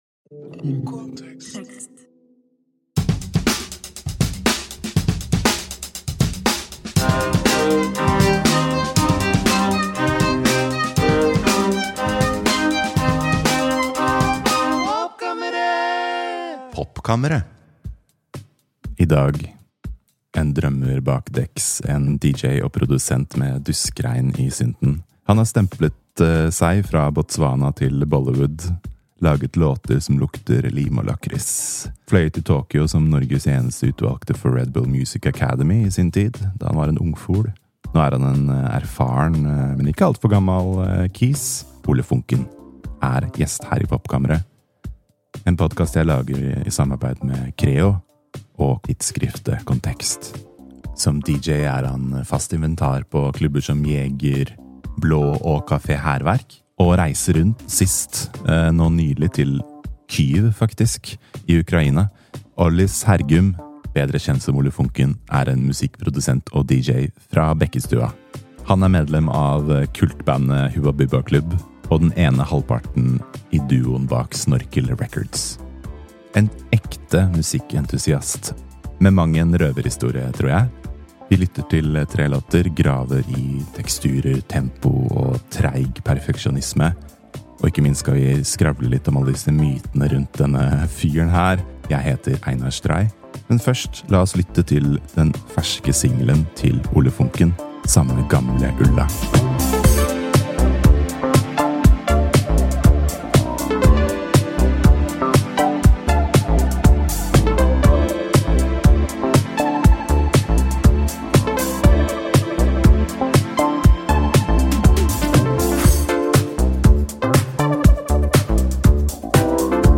En særs inspirerende og gøyal, dypdykkende prat med den unge ringreven.